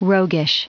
Prononciation du mot roguish en anglais (fichier audio)
Prononciation du mot : roguish